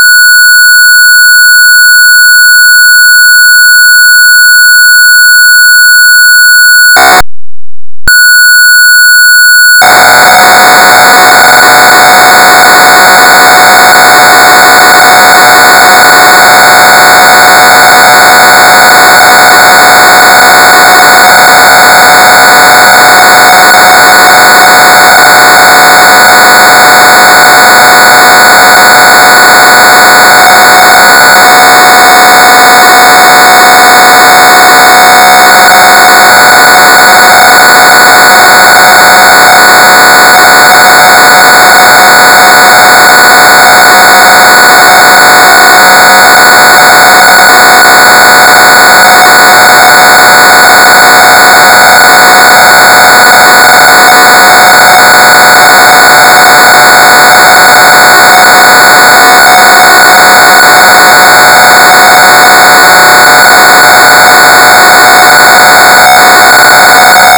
ПК-01 Львов WEB Tape Loader